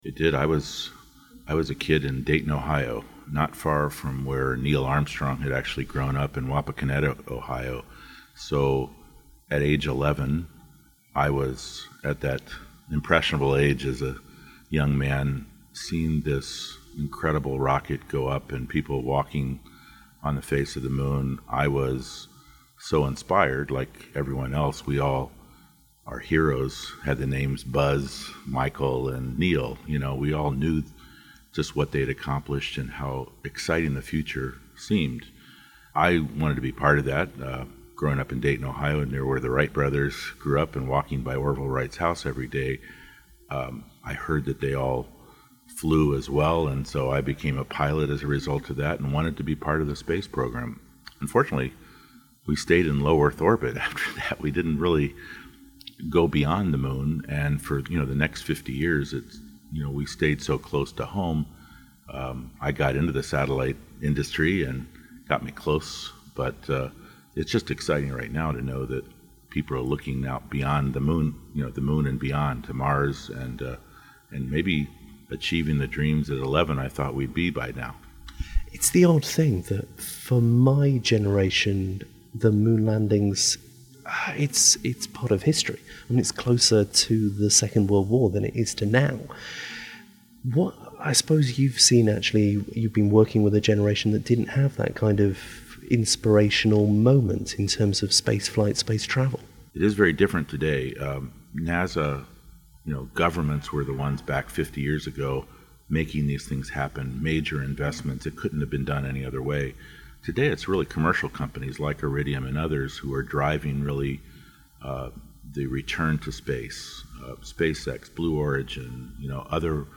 Extended Interview - part broadcast first 19th July 2019